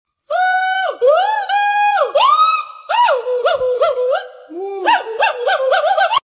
Monkey sound meme green screen sound effects free download